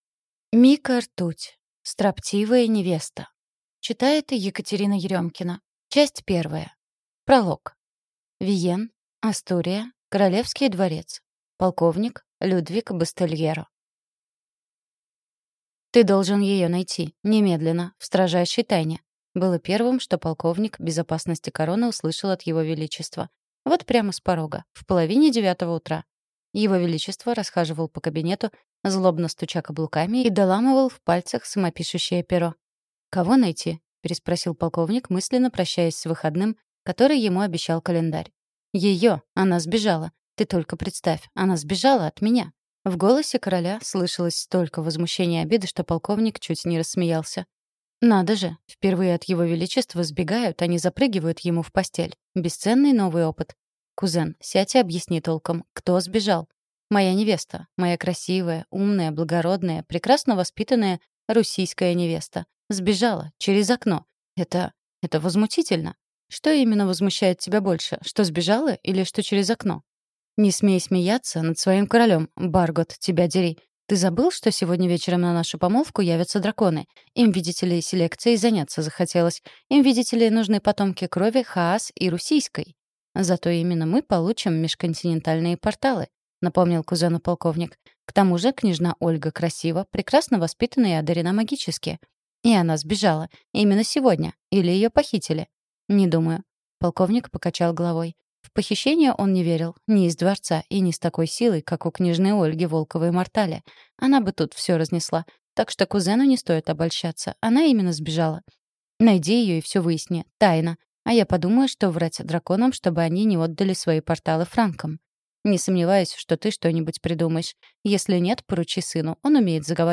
Аудиокнига Строптивая невеста | Библиотека аудиокниг